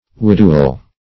Widual \Wid"u*al\, a. Of or pertaining to a widow; vidual.